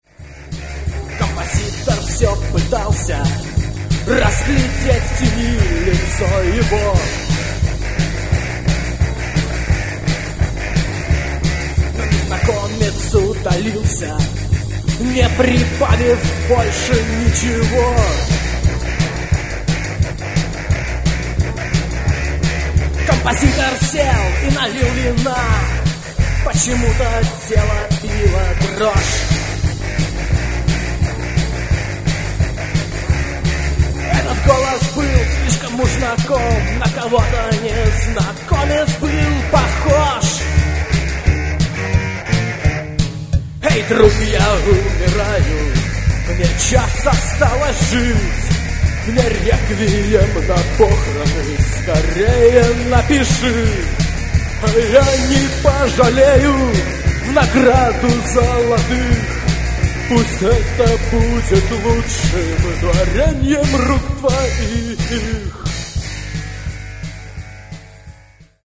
Гитары, бас, вокал
Барабаны